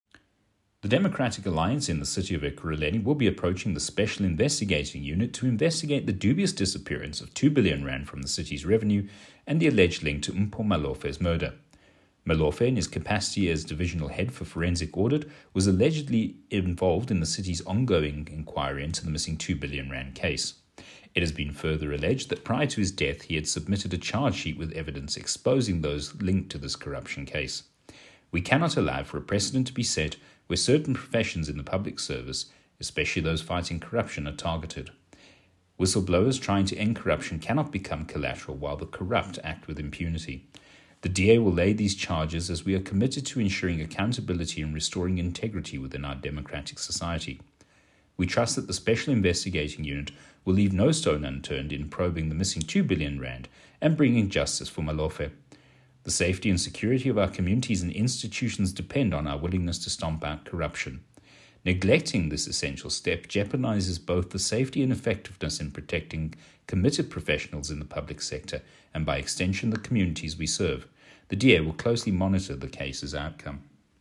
Note to Editors: Please find an English soundbite